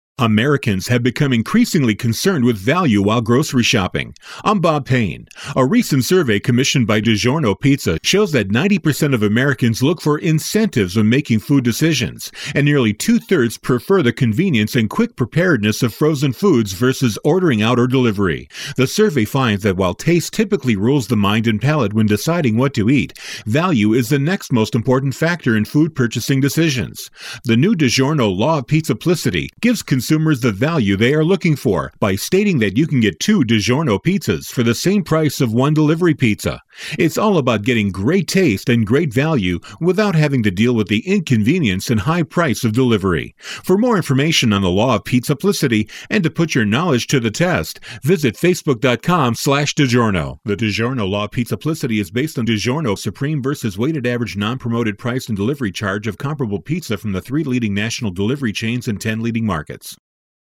September 21, 2012Posted in: Audio News Release